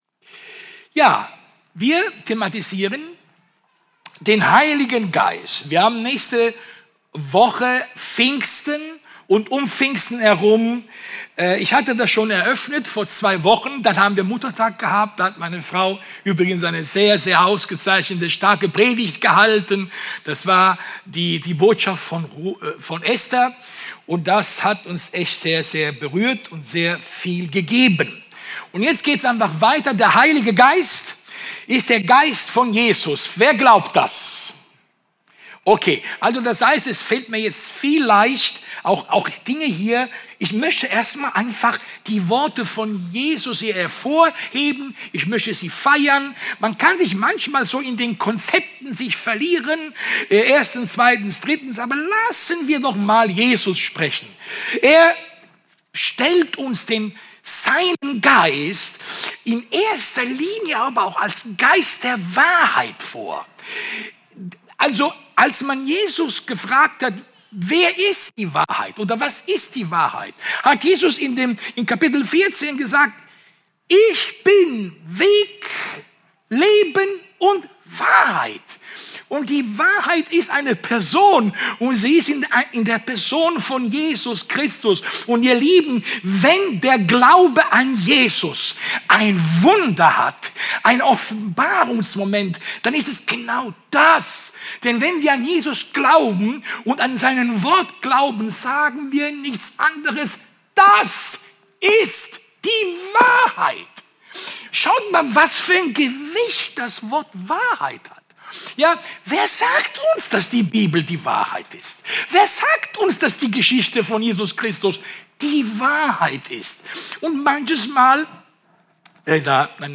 Passage: Johannes 14, 15-17; Johannes 20, 21-22; Epheser 1, 13; Römer 8, 14-16 Dienstart: Sonntag